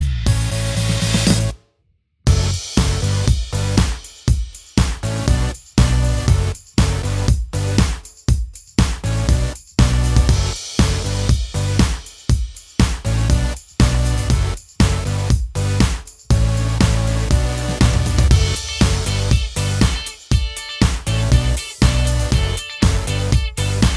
Minus All Guitars For Guitarists 3:30 Buy £1.50